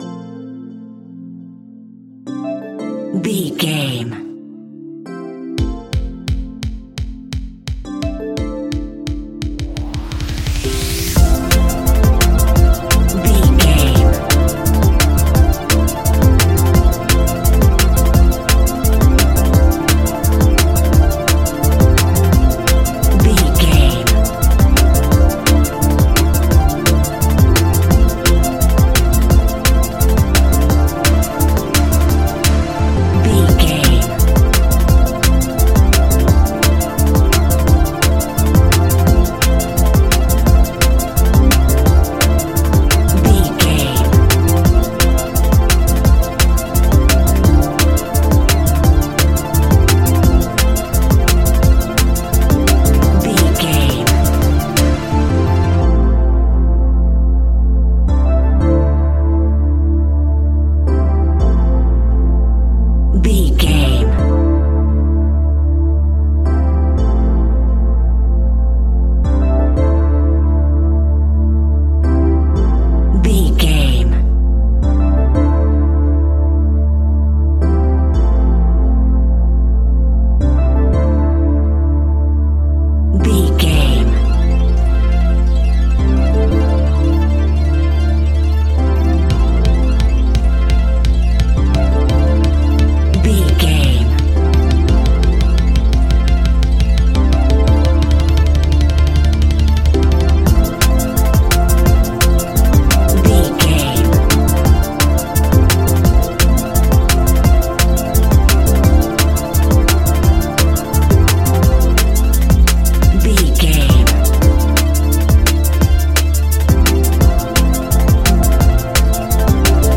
Ionian/Major
A♯
electronic
techno
trance
synths
synthwave